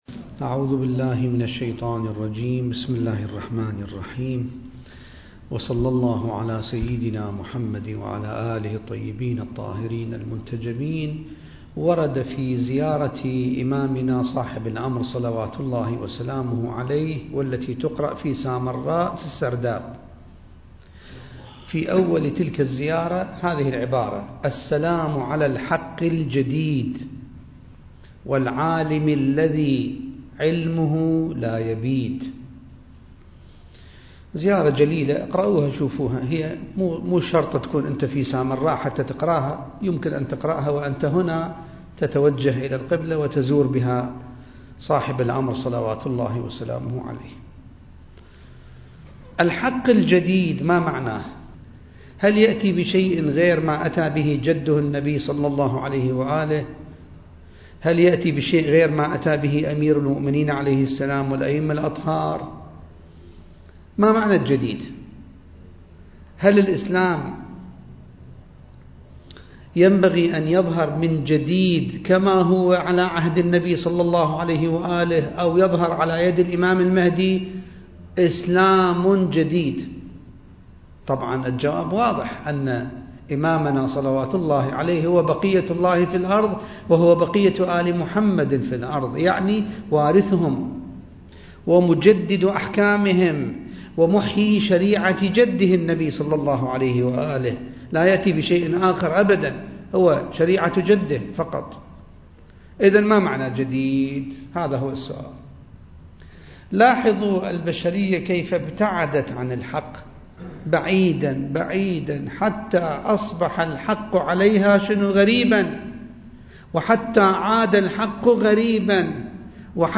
المكان: جامع الصاحب (عجّل الله فرجه) - النجف الأشرف التاريخ: 2021